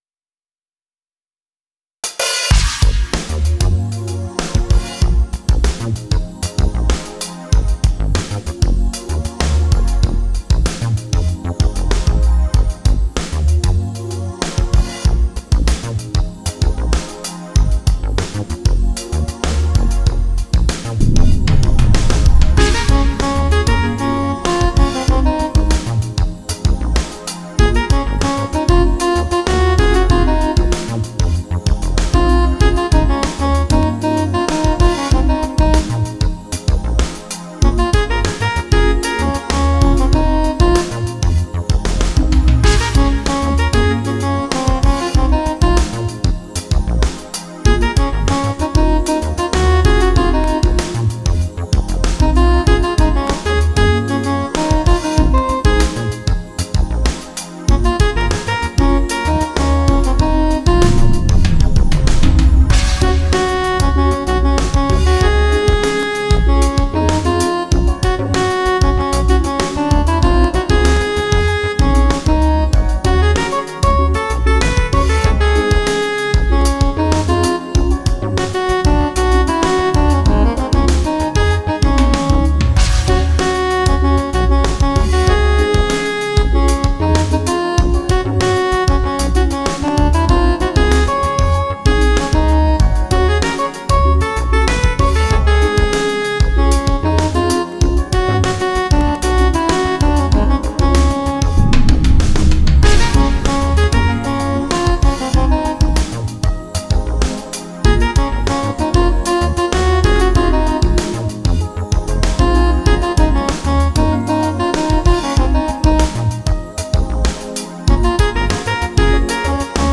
MIDIファイルからDAWに取り込んでノートは触らず整えた。
音源はIK SampleTank 3。